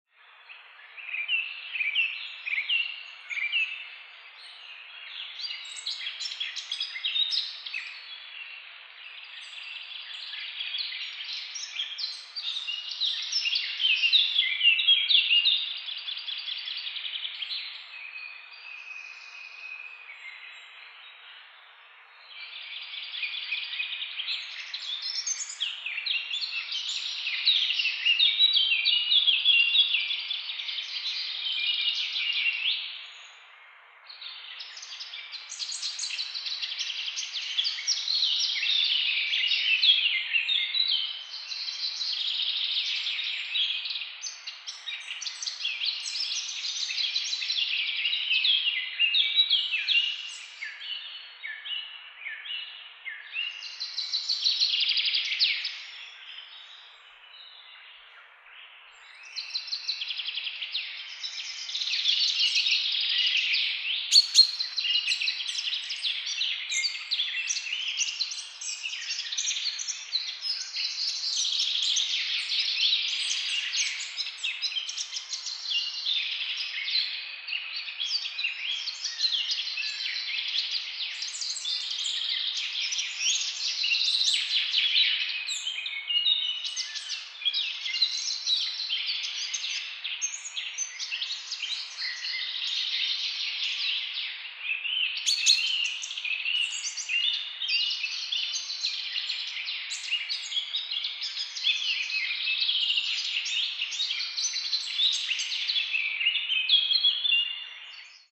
Birds Chirping Nature Sounds
Description: Birds chirping nature sounds. Relaxing birdsong in a spring forest or park. Perfect for unwinding, meditation, sleep, or simply creating a calming ambiance. Morning nature sounds. Relaxing spring atmosphere.
Genres: Sound Effects
Birds-chirping-nature-sounds.mp3